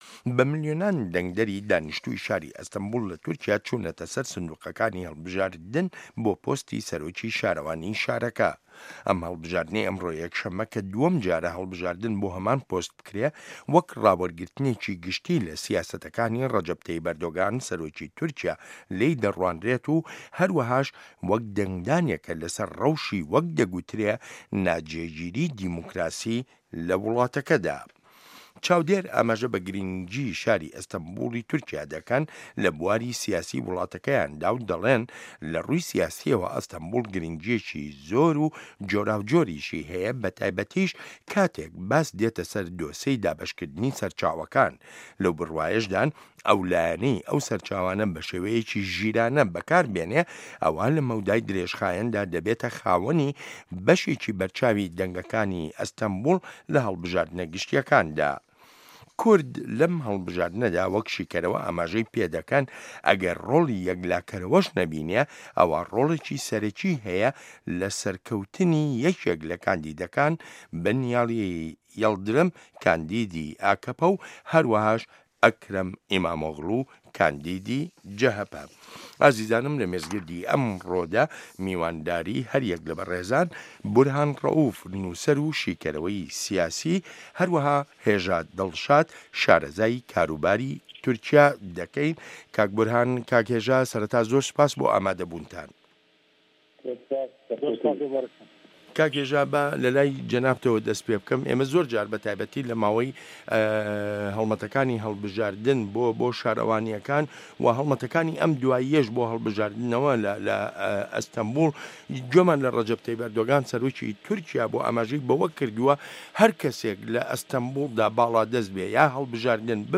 مێزگرد: ئەستانبوڵ لە هاوکێشەی سیاسی تورکیادا